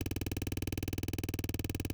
text-scrol-mechanical.wav